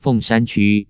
使用說明 您輸入一個以上的中文字，多音字之發音以人工智慧技術判斷，結果僅供參考
::: 請輸入欲查詢漢字、注音或拼音（20字為限） 顯示聲調符號 不顯示聲調符號 查詢結果 查詢文字 鳳山區 注音 ㄈㄥˋ ㄕㄢ 。 ㄑㄩ 漢語拼音 fèng shān qū 通用拼音 fòng shan cyu 注音二式 fèng shān chiū 威妥瑪拼音 feng 4 shan ch ü 耶魯拼音 fèng shān chyū 發音 使用說明 您輸入一個以上的中文字，多音字之發音以人工智慧技術判斷，結果僅供參考